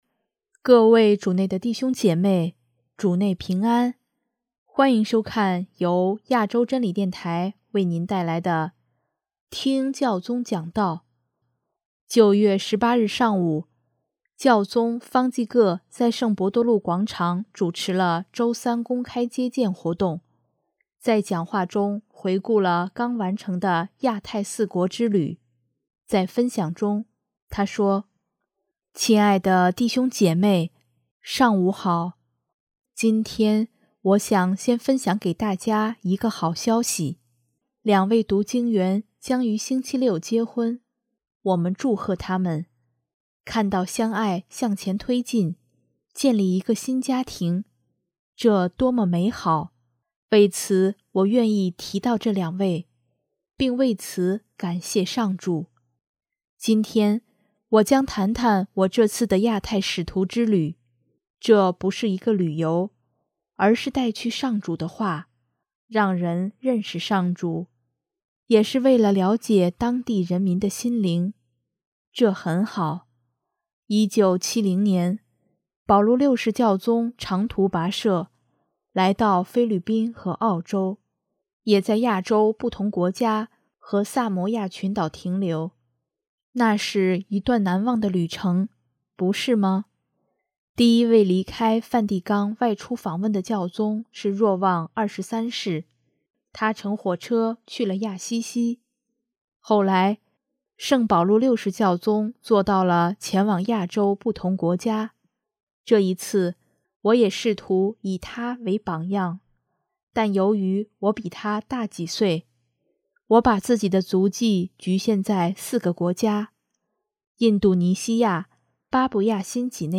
9月18日上午，教宗方济各在圣伯多禄广场主持了周三公开接见活动，在讲话中回顾了刚完成的亚太四国之旅。